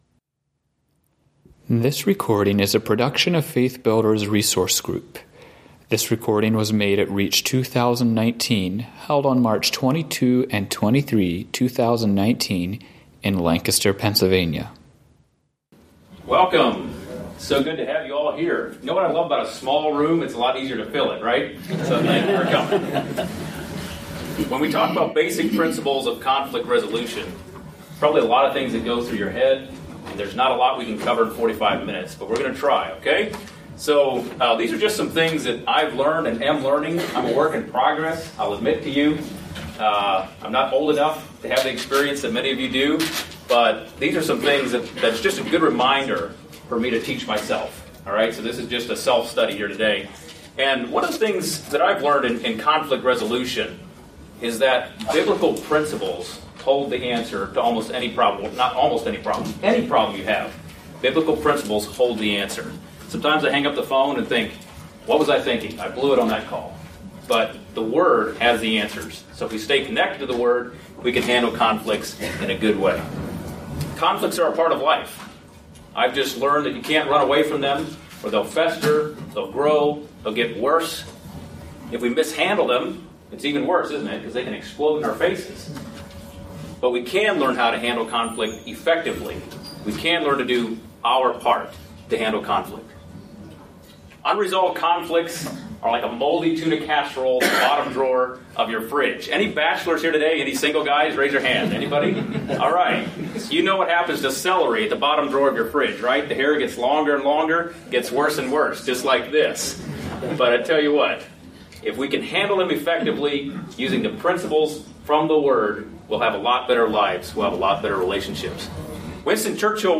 Home » Lectures » Basic Principles of Conflict Resolution